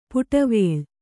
♪ puṭavēḷ